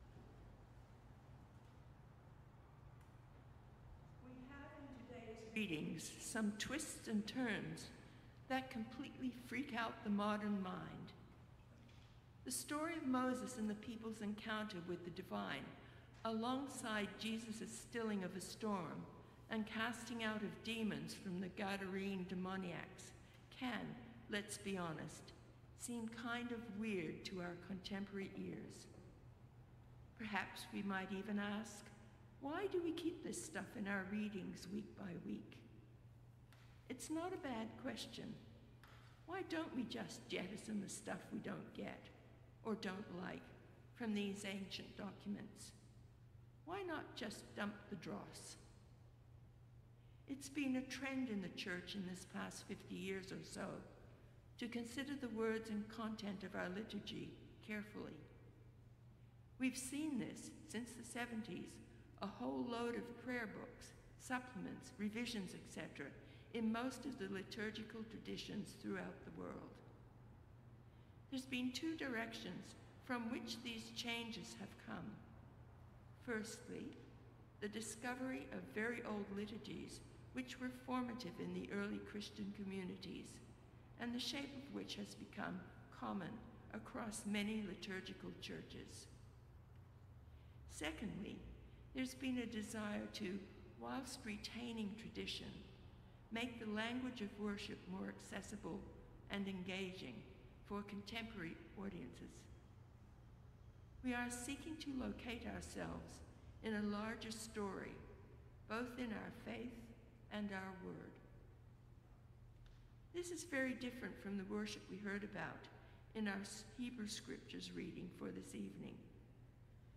Evensong Reflection